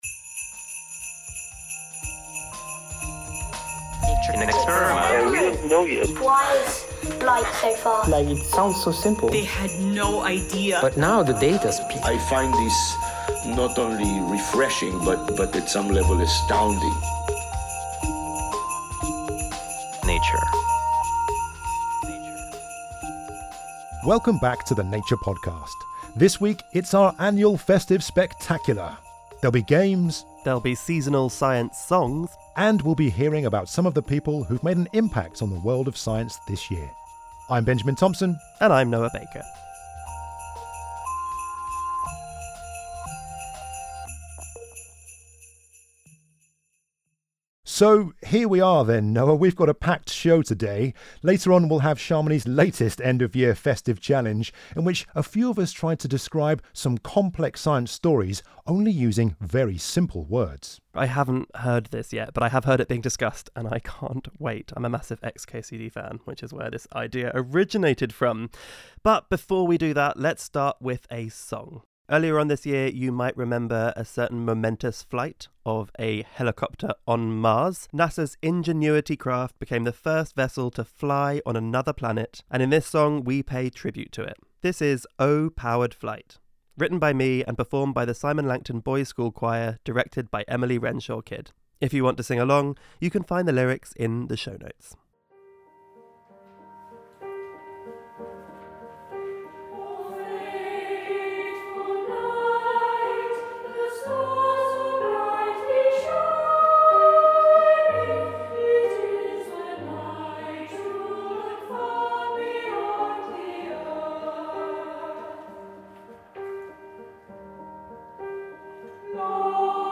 Games, seasonal science songs, and Nature’s 10.